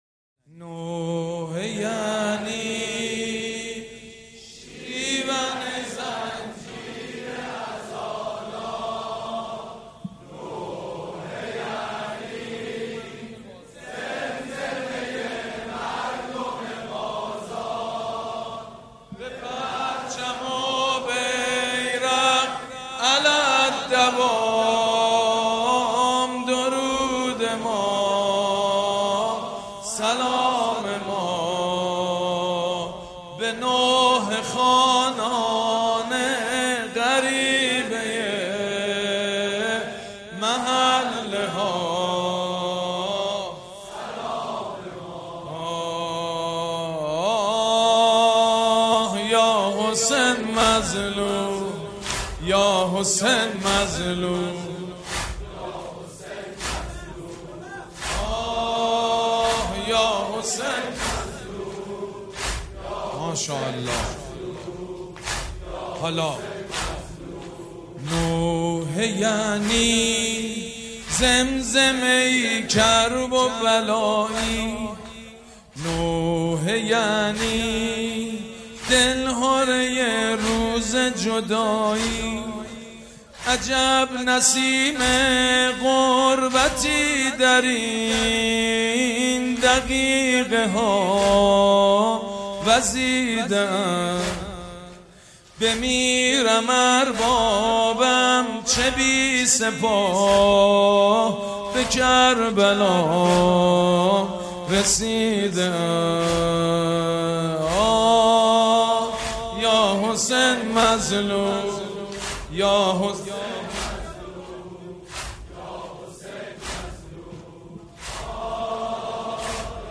شب دوم محرم الحرام در هیئت ریحانه الحسین علیه السلام
مداحی